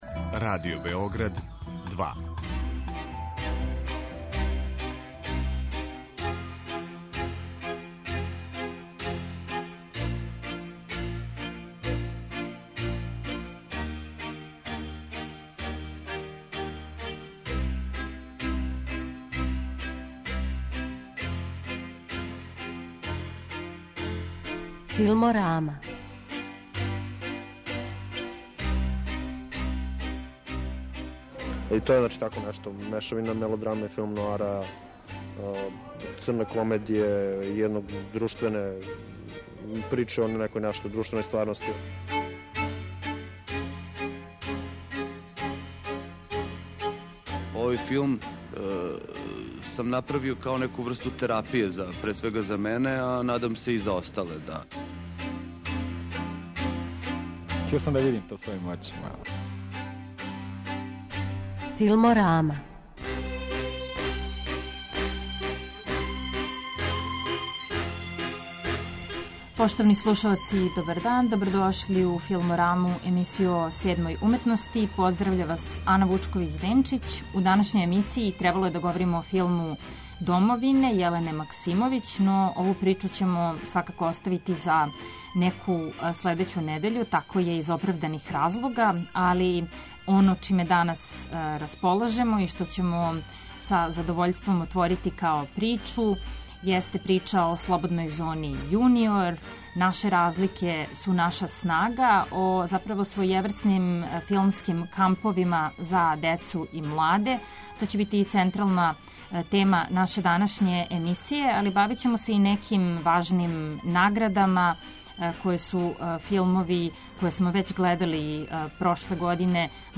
Филморами